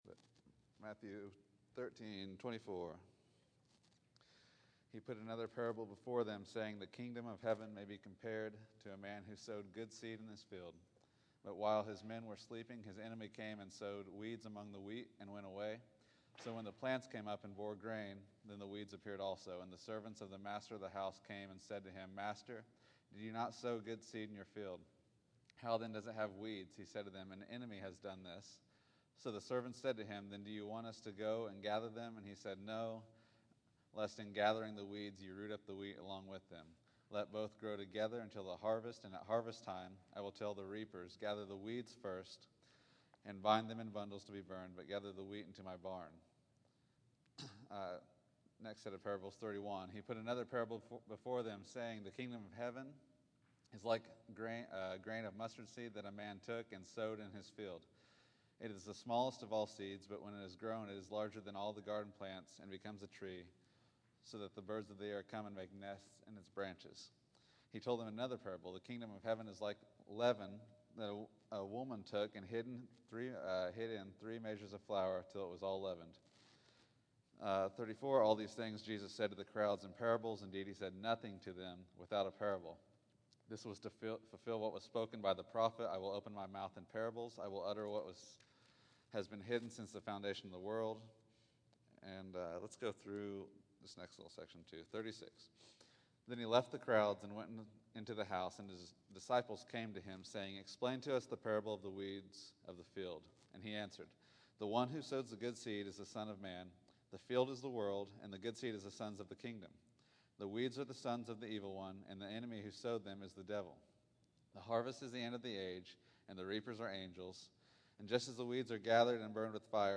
Matthew 13:24-46 September 15, 2013 Category: Sunday School | Location: El Dorado Back to the Resource Library Jesus uses parables to show the great worth and unexpected timing of the Kingdom.